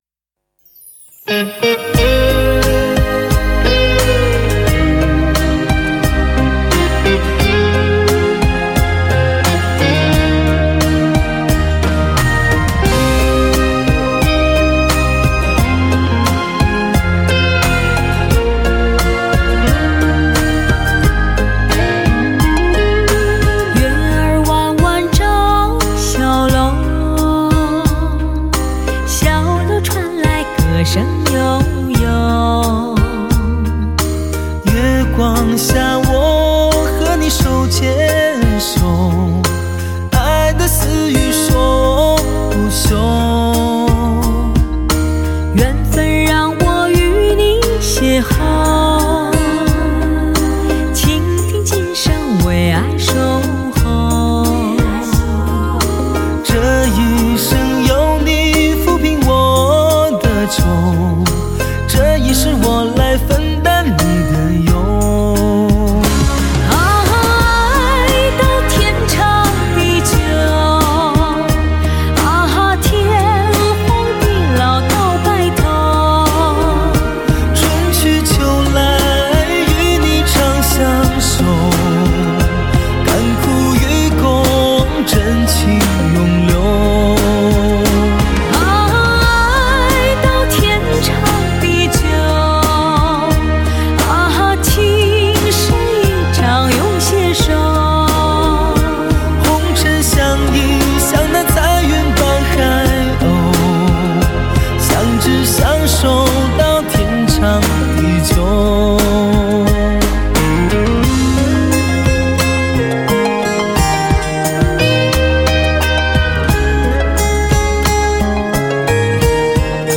发烧级殿堂男声，点燃原创发烧味道。